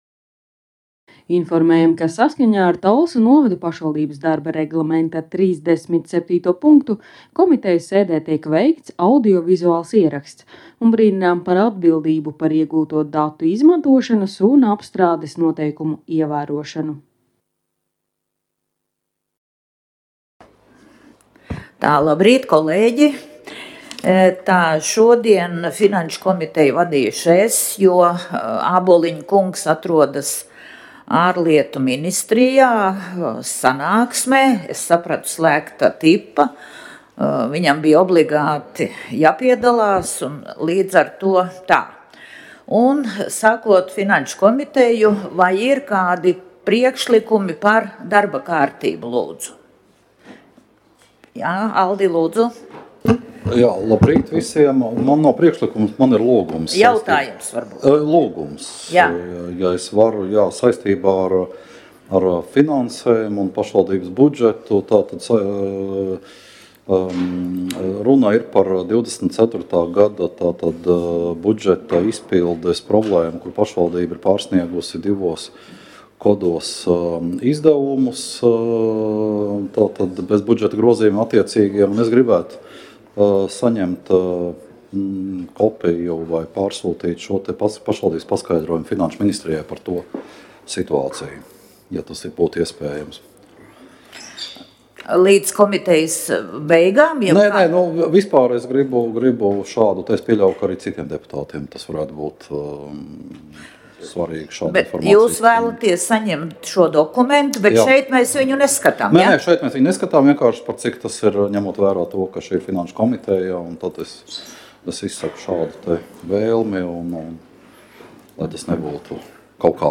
Komitejas sēdes audio